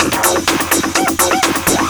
DS 126-BPM B2.wav